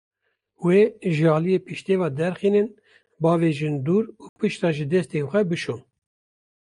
Pronounced as (IPA) /duːɾ/